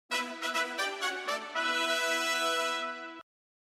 Largo [0-10] - - armee - epique - guerres - combat